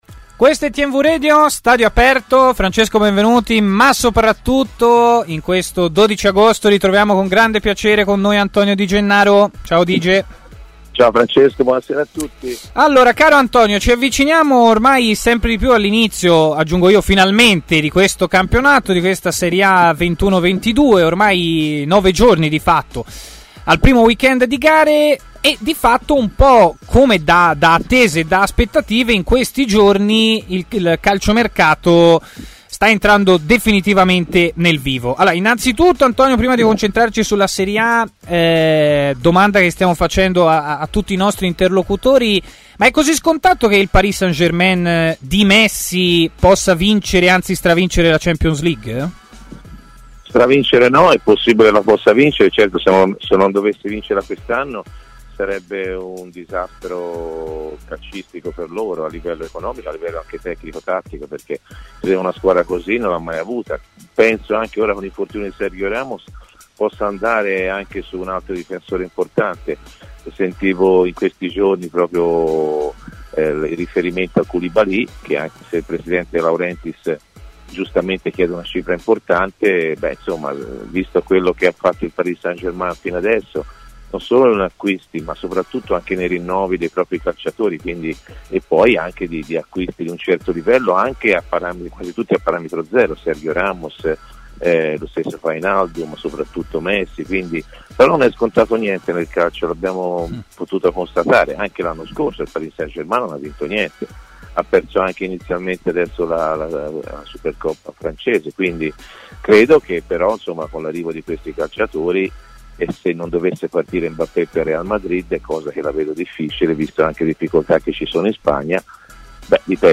Antonio Di Gennaro , ai microfoni di TMW Radio, ha parlato degli argomenti caldi relativi al mercato e alla Serie A che sta per cominciare.